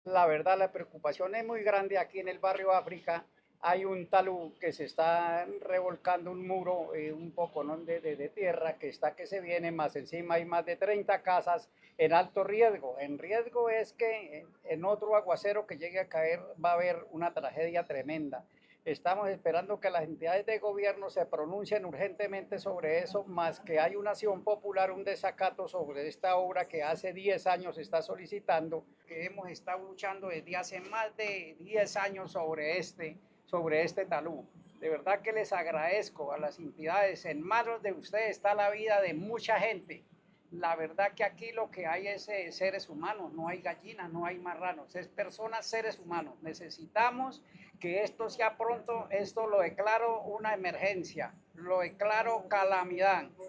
Prudencio Martínez, edil comuna 8 de Bucaramanga